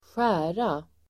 Uttal: [²sj'ä:ra]